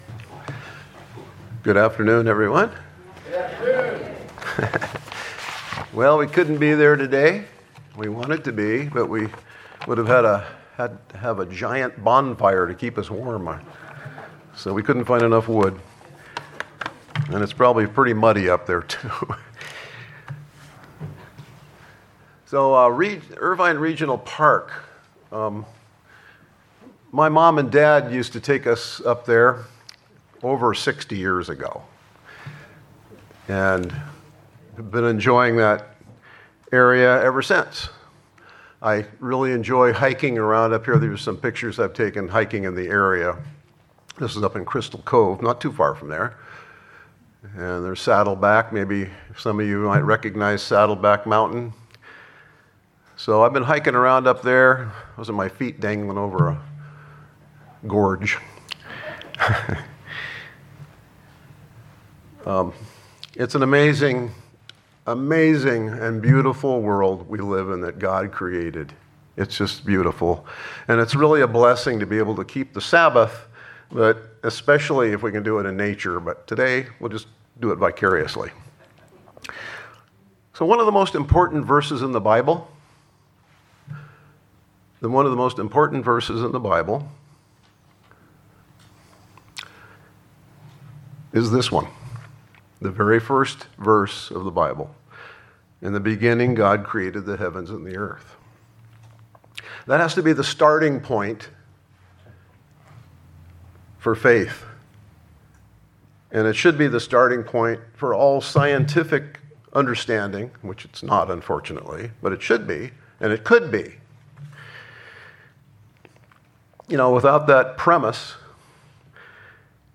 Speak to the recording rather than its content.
Given in Orange County, CA